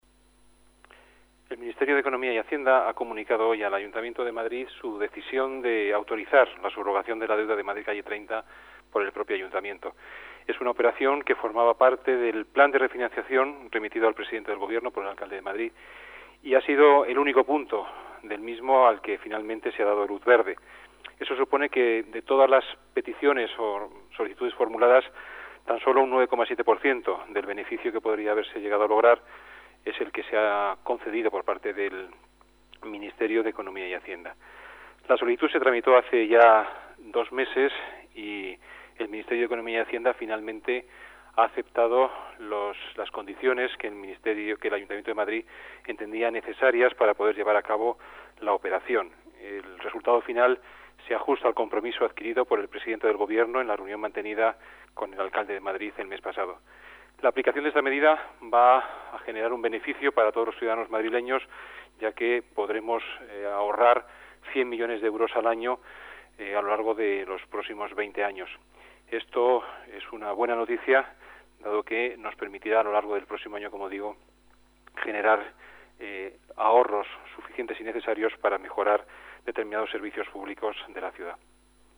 Nueva ventana:Juan Bravo, delegado de Hacienda. Subrogación de la deuda de Calle 30